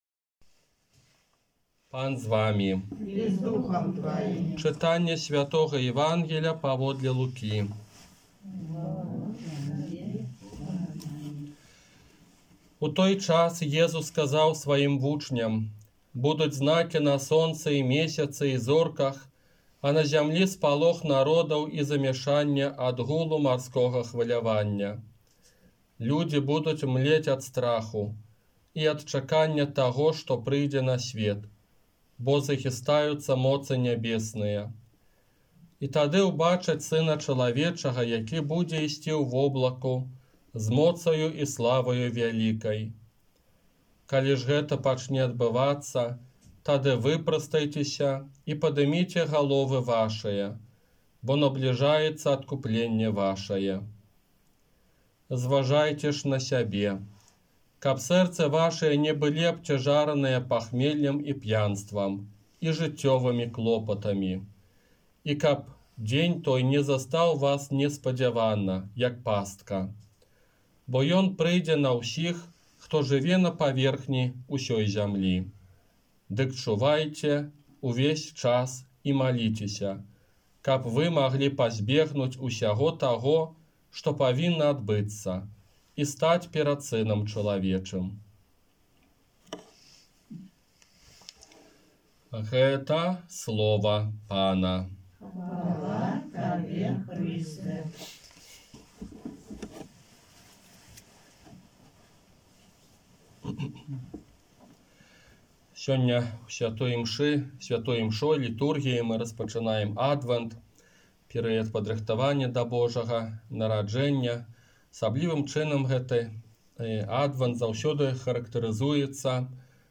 Казанне на першую нядзелю Адвэнта